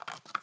* Patch packs also have sounds
* Increased patch packs volume by 4db